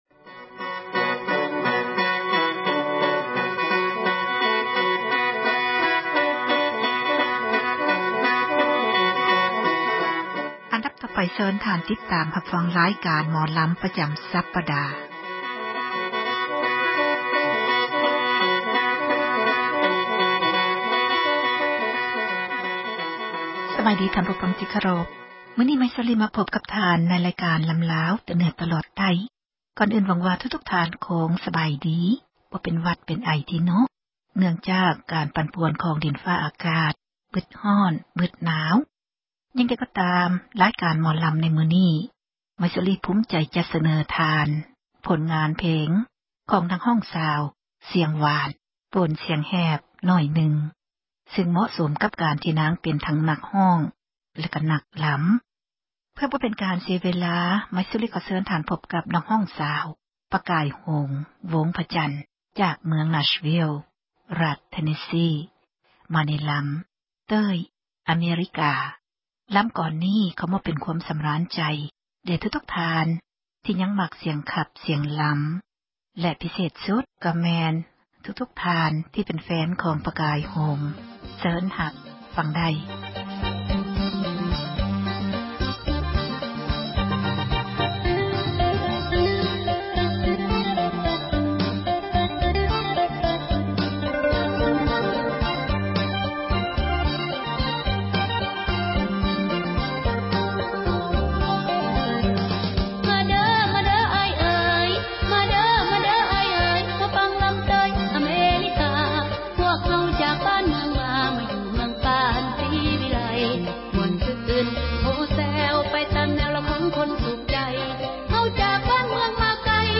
ສິລປະ ການຂັບລໍາ ຂອງ ເຊື້ອສາຍ ລາວ ໃນແຕ່ລະ ຊົນເຜົ່າ ແຕ່ລະ ພື້ນເມືອງ.